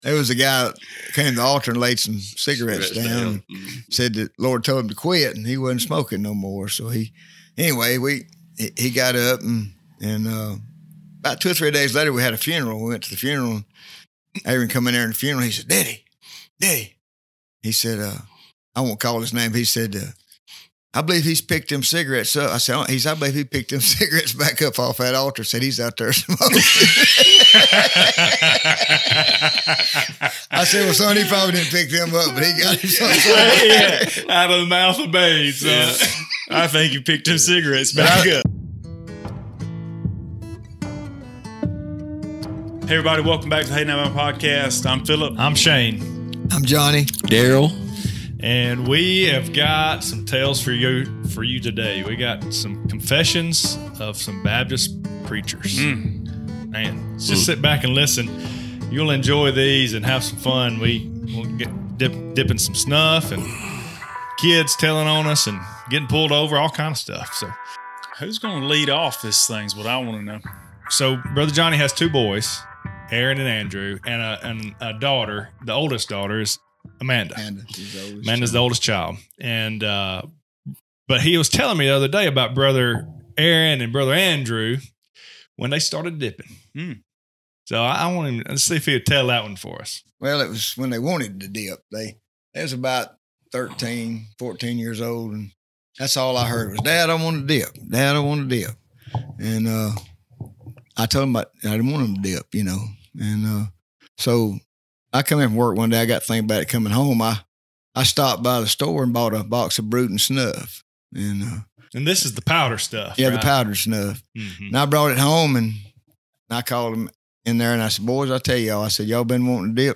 Need a refreshing laugh? Pull up a chair and join in on this conversation.